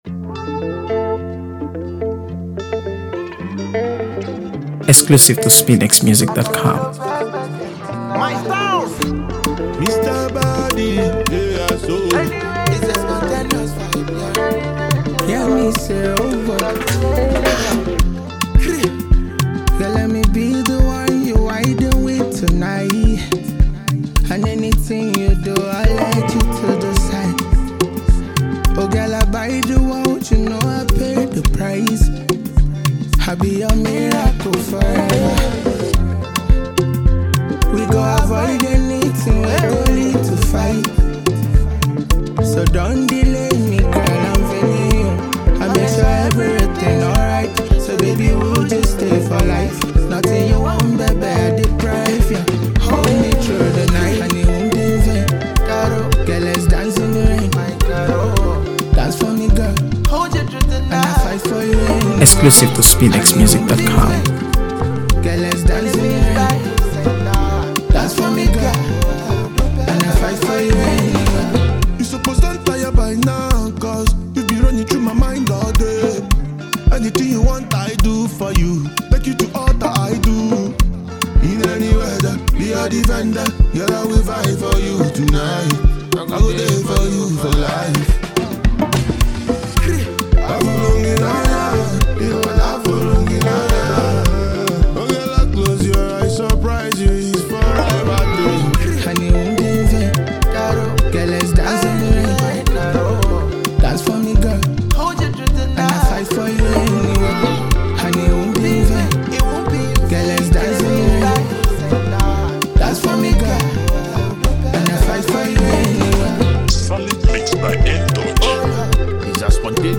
AfroBeats | AfroBeats songs
With its infectious rhythm and smooth delivery
Afrobeat and contemporary Nigerian music